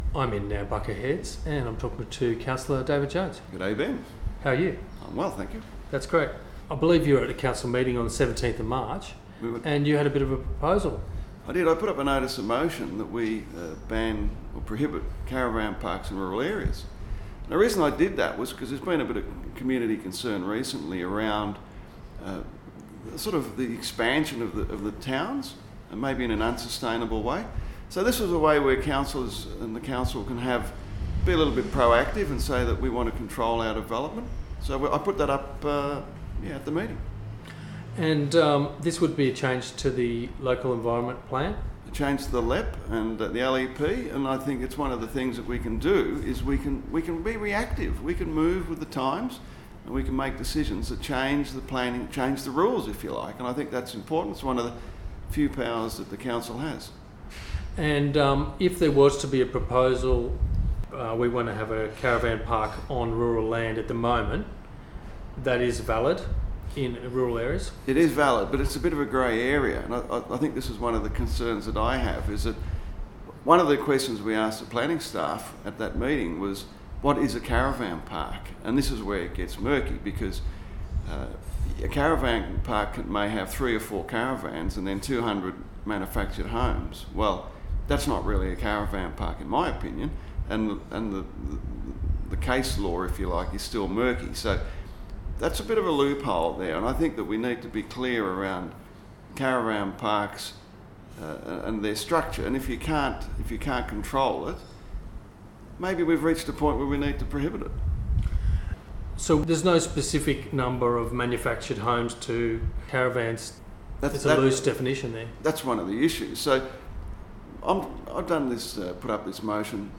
Interview with Councillor David Jones on 2NVR Community Radio